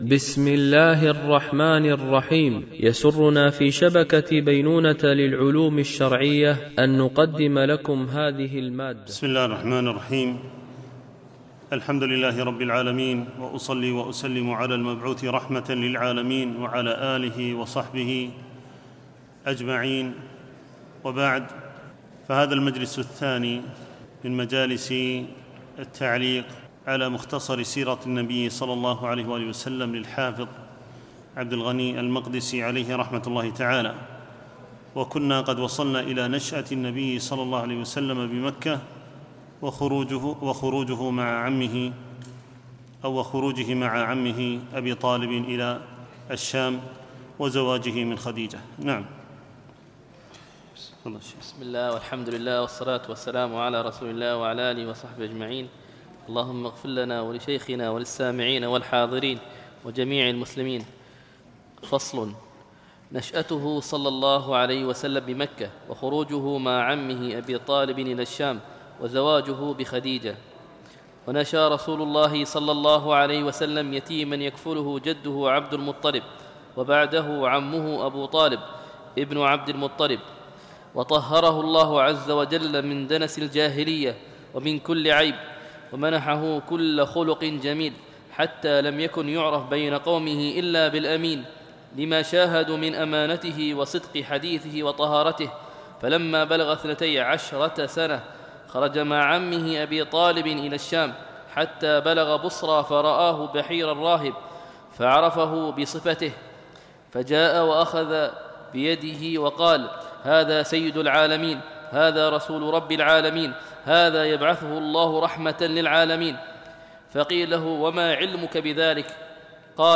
شرح مختصر سيرة النبي ﷺ ـ الدرس 02